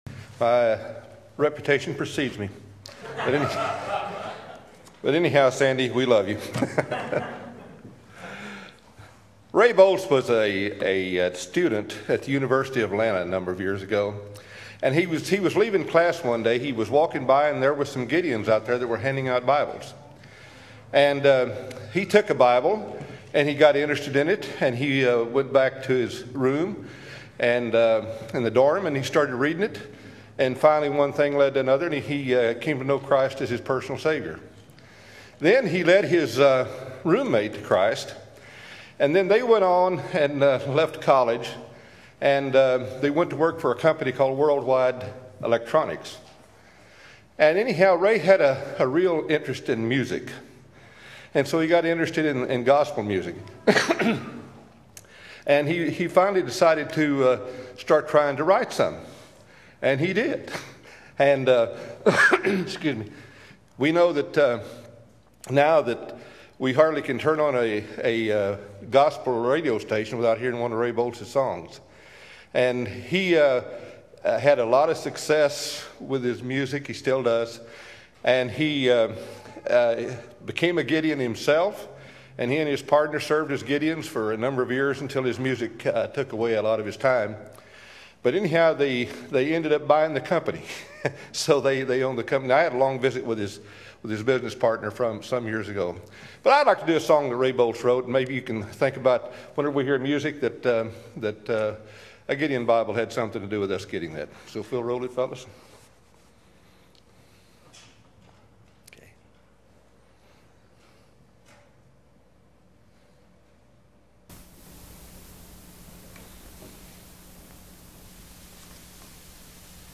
2007 Guest Speakers http